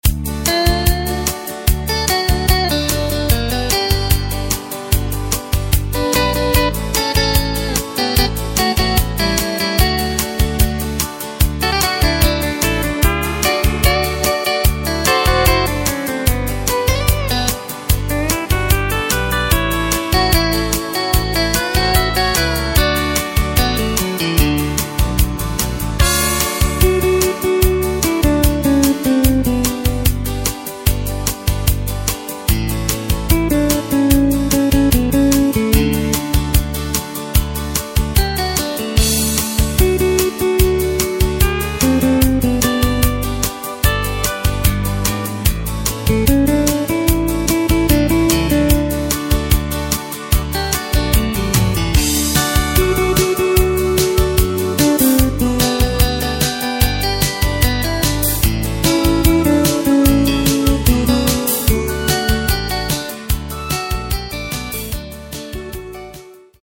Takt:          4/4
Tempo:         148.00
Tonart:            D
Austropop aus dem Jahr 1991!